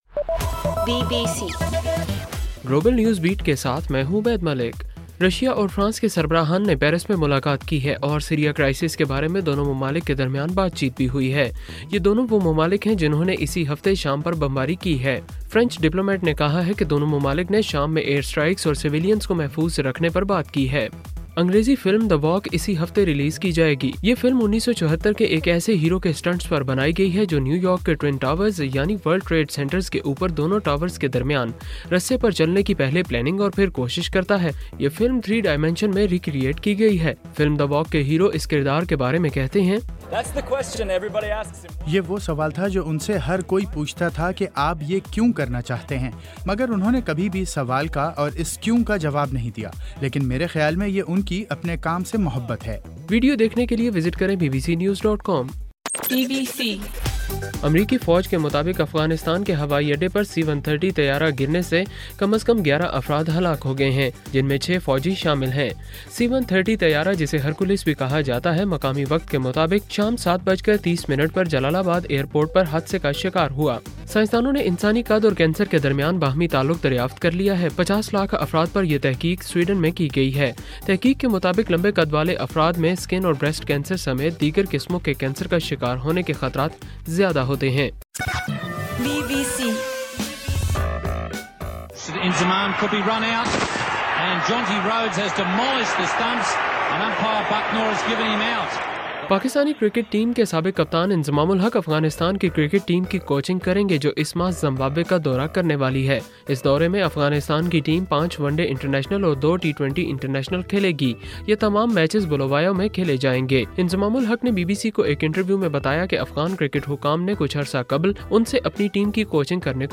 اکتوبر 2: رات 10 بجے کا گلوبل نیوز بیٹ بُلیٹن